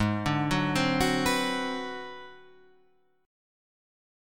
G#7#9b5 chord